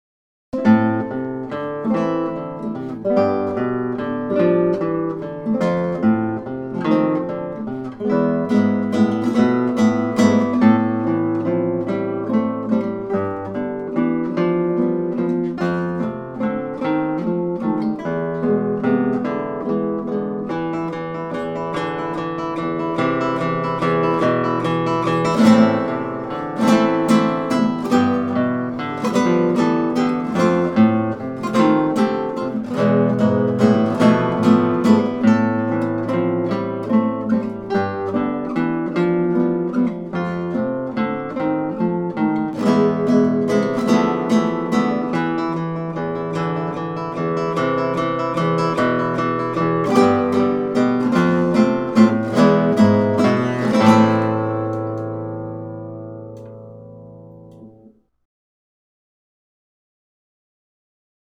Use your left-hand thumb to pluck the bass-heavy melody. For the chords, a simple strum on beats 1 and 4 is suggested.
6/4 Meter